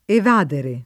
evadere [ ev # dere ] v.;